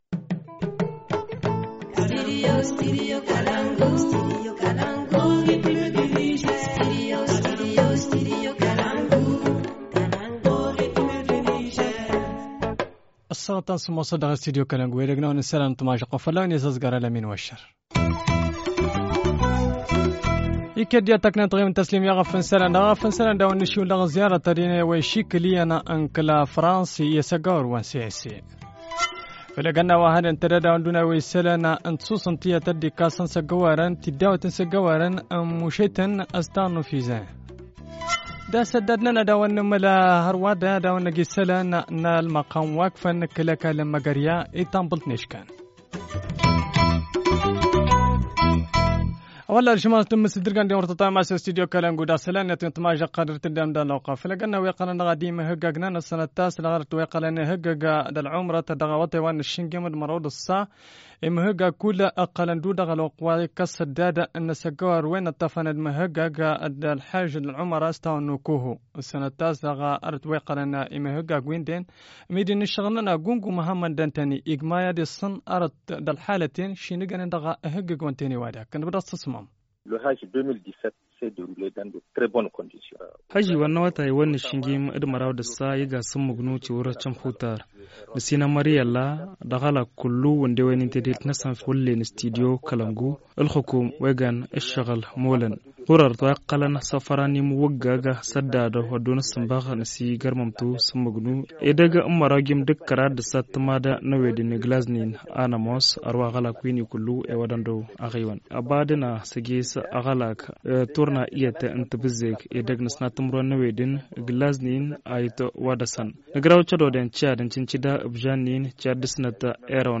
Journal du 29 septembre 2017 - Studio Kalangou - Au rythme du Niger